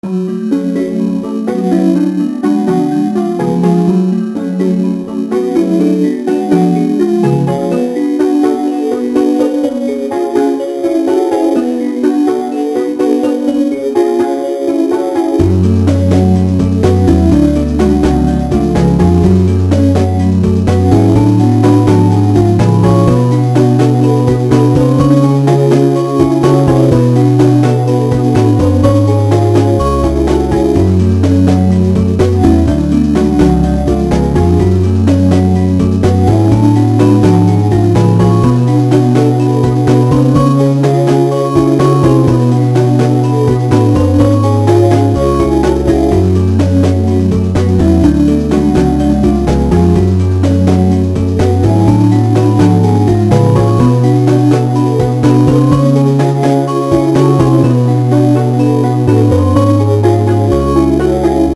this was also made for the game! it's supposed to be lobby music and i think it's really cute ^_^